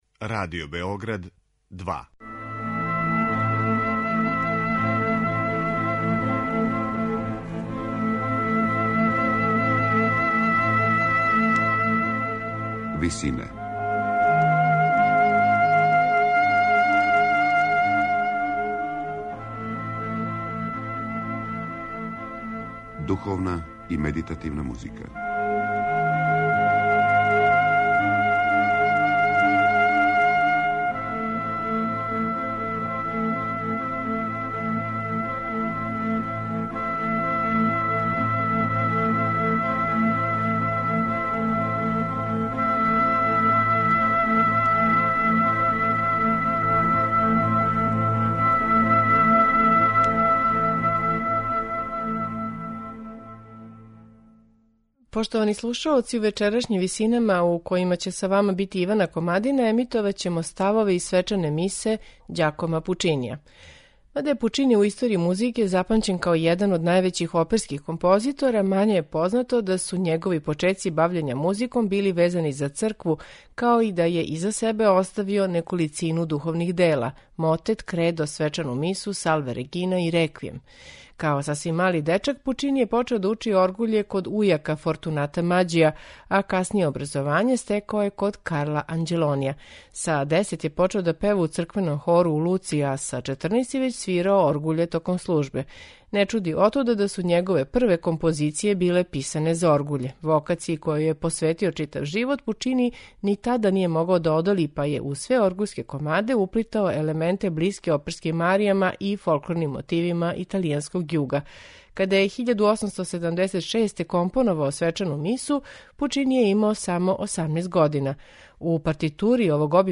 Свечана миса
Оно што никоме није могло да промакне била је страст према опери, коју Пучини ни овде није успео да обузда: деонице вокалних солиста писане су у јасном белакнто стилу, а два најобимнија става Глориа и Кредо, представљају праве оперске сцене.
сопран
тенор
бас